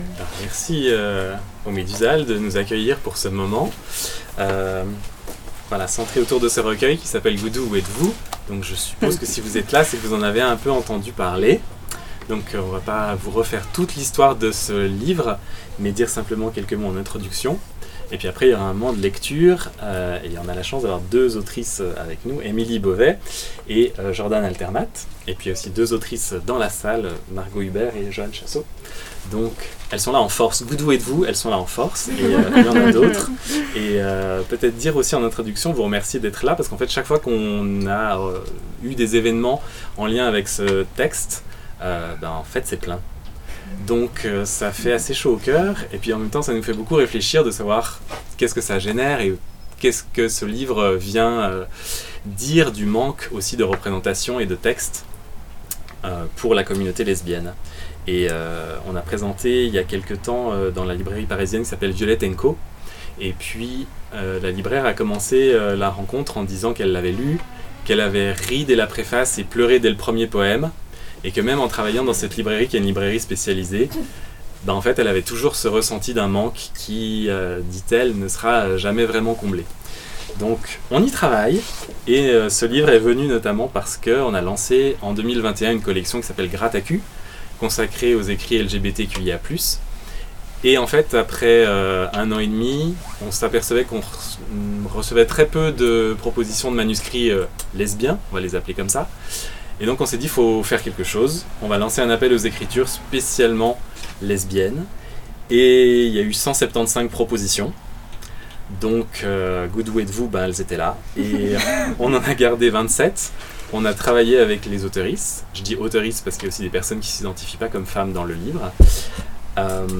Présentation du livre "Goudous, où êtes-vous?" par Paulette éditrice et lecture des autrices